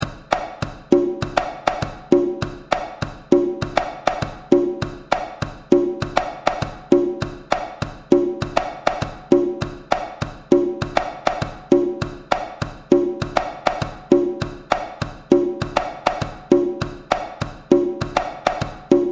GENERAL INFO The Bomba Holandes is a rumba of bomba ORIGIN Puerto Rico RHYTHM INFO Traditional pattern for one drum
GENERAL INFO The Bomba Holandes is a rumba of bomba ORIGIN Puerto Rico RHYTHM INFO Traditional pattern for one drum SHEET MUSIC (for key notation click here ) 2-3 SON CLAVE AUDIO FILE AVAILABLE!